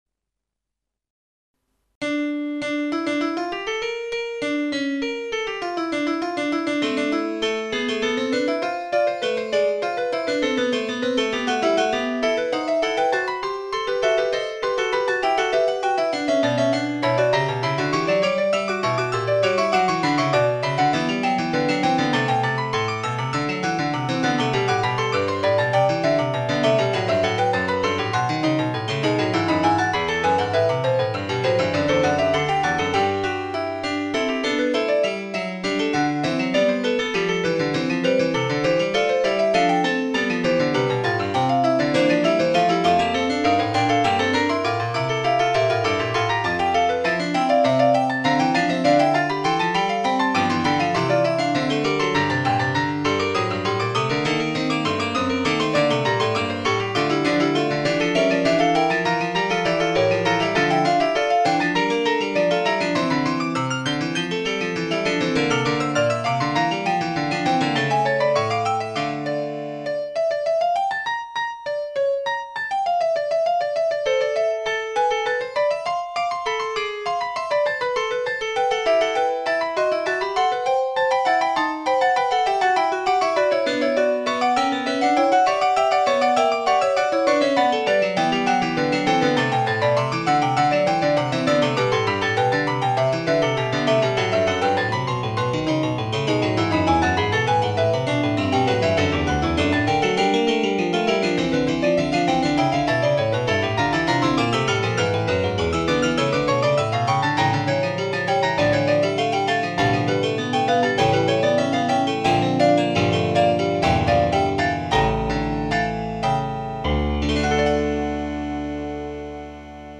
Fuga d-moll
Starałem się uzyskać efekt stereo i w tym celu stosowałem opóźnienie poszczególnych głosów o 20 milisekund w lewym bądź prawym kanale. Z tego powodu utwór zapisałem w formacie MP3 ale barwa dźwięku i tak jest generowana przez MIDI.